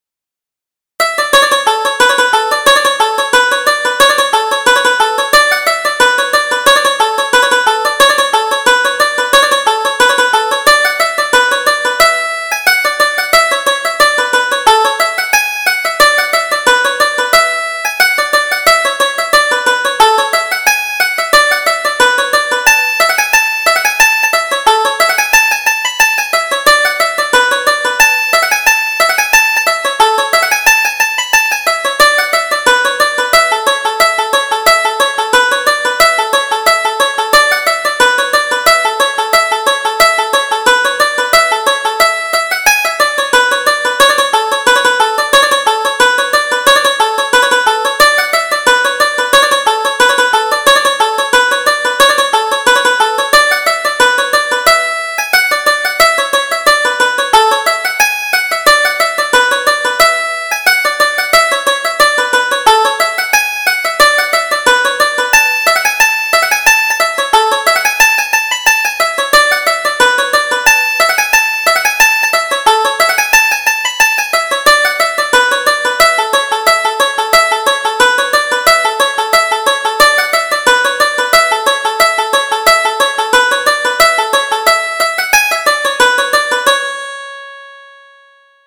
Reel: The Four Courts - 2nd Setting